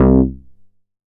MoogUgly 009.WAV